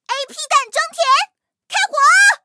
M4谢尔曼开火语音2.OGG